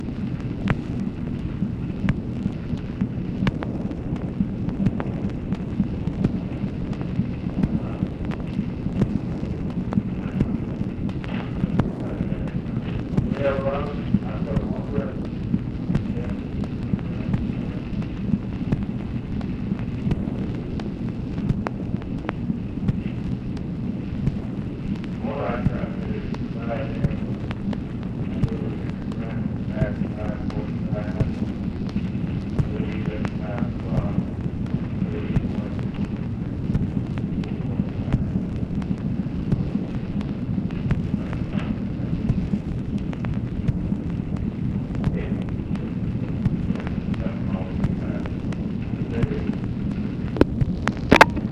OFFICE CONVERSATION, June 17, 1965
Secret White House Tapes | Lyndon B. Johnson Presidency